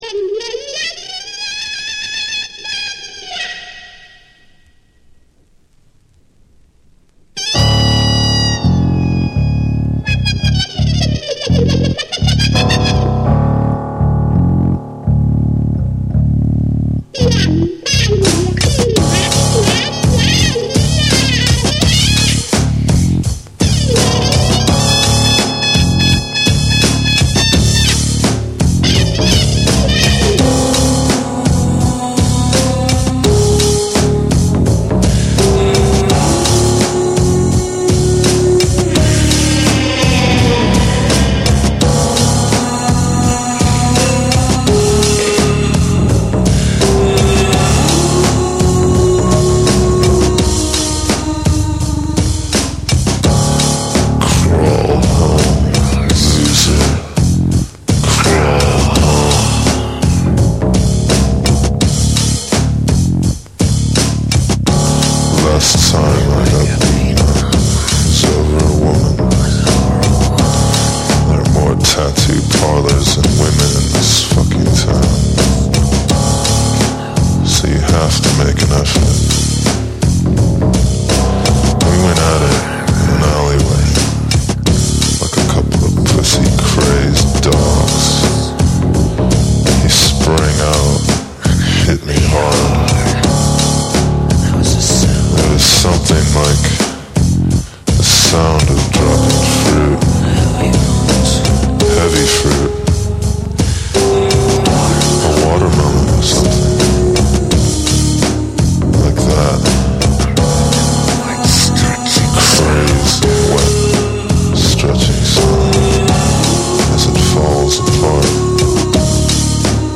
ジャズやアンビエントが交錯する実験的なビートダウンを展開し、ドープかつ幻想的な質感が全編に漂うブレイクビーツを収録。
BREAKBEATS